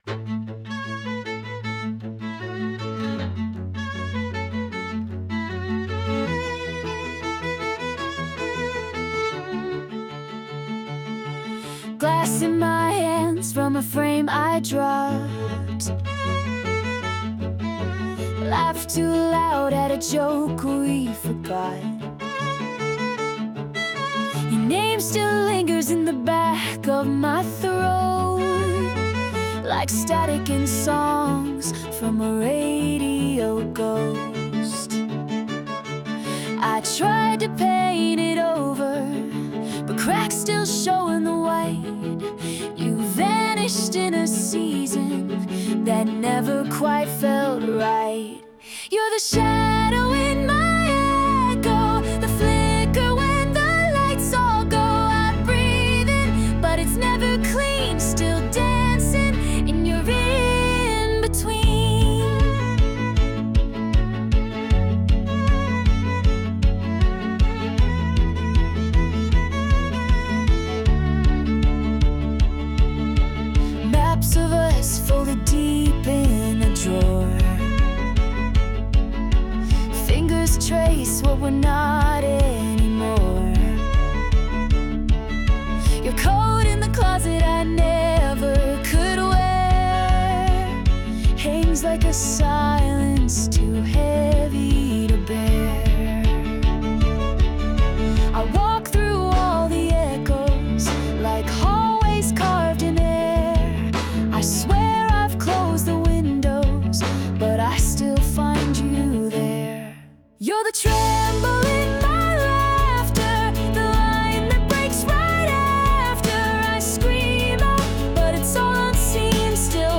女性ボーカル洋楽洋楽 女性ボーカル作業BGMポップスアップテンポ元気おしゃれ切ないノスタルジック
著作権フリーオリジナルBGMです。
女性ボーカル（洋楽・英語）曲です。
クラシカルな旋律と現代的なビートが混ざり合う音作りが好きな方には、印象的な一曲になるはずです。